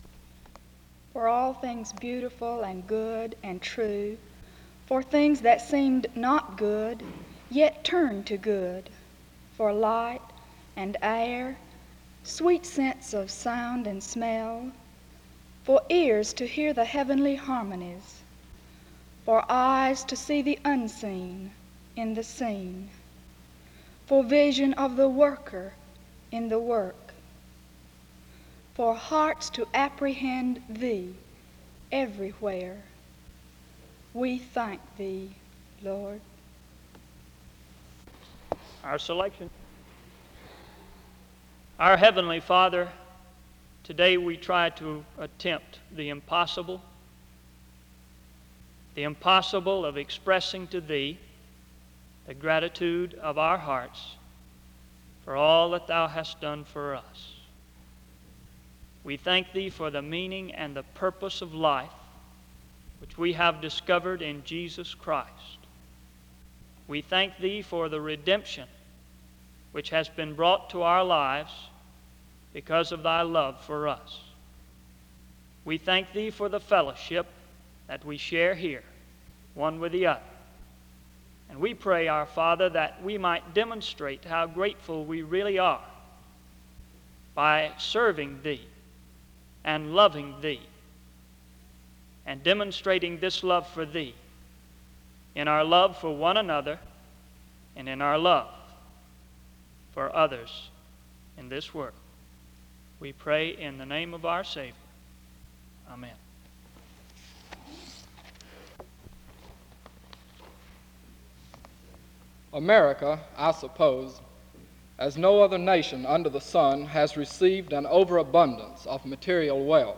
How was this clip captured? SEBTS Chapel - Student Day November 25, 1959